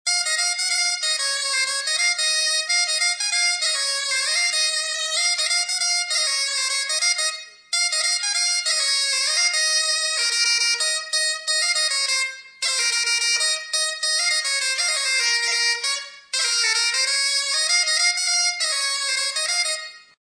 La bombarde
L'instrument à vent, qui fait partie de la famille des hautbois.
bombarde.wav